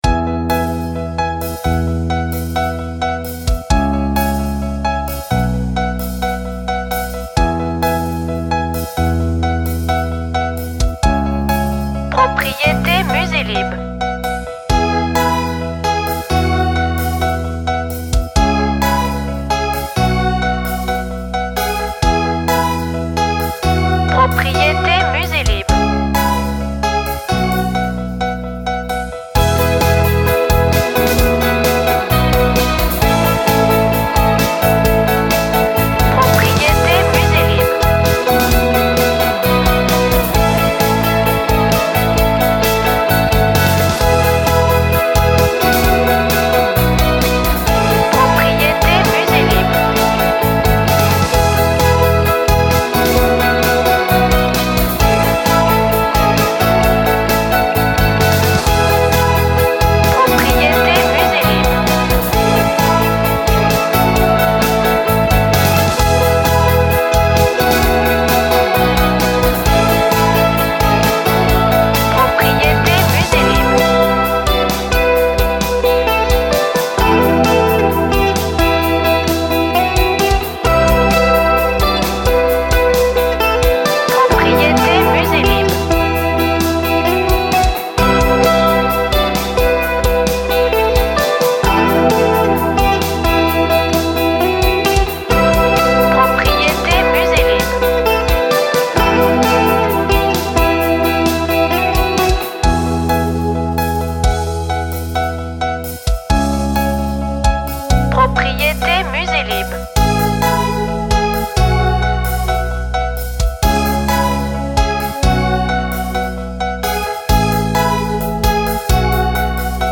Pop tonique et espiègle!